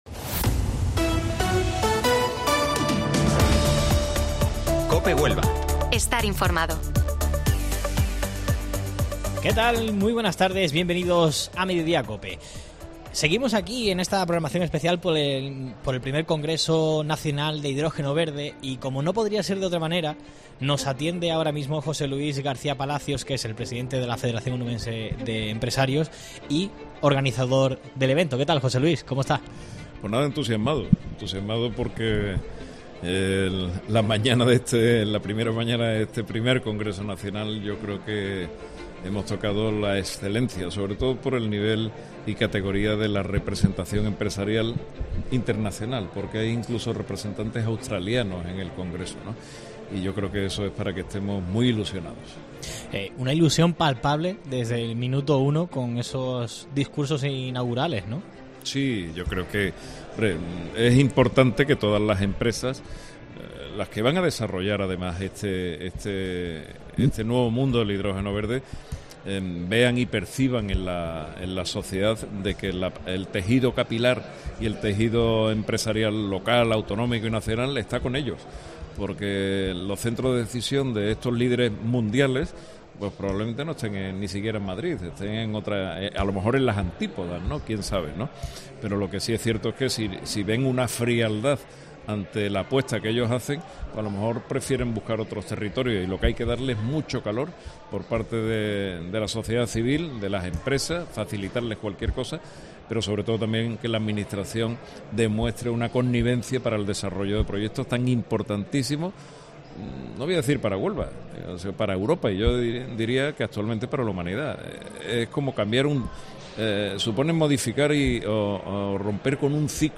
Hablamos desde el I Congreso Nacional de Hidrógeno Verde